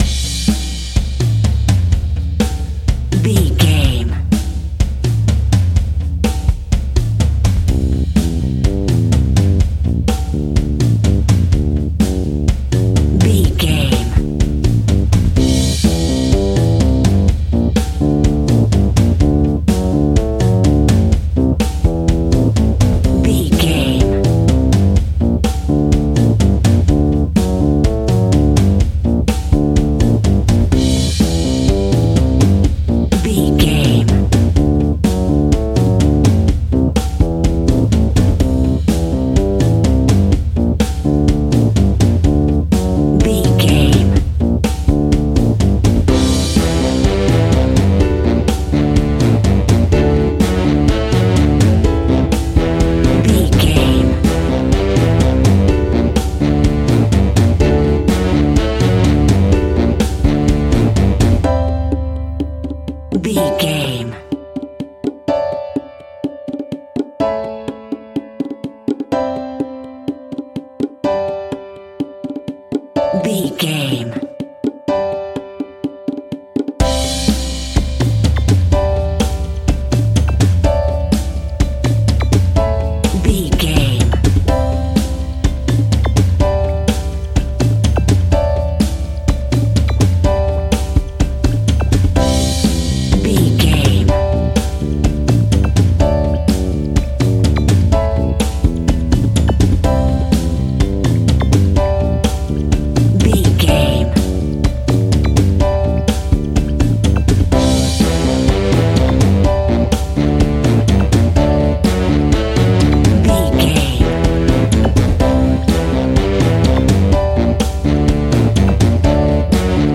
Aeolian/Minor
salsa
drums
bass guitar
electric guitar
piano
hammond organ
percussion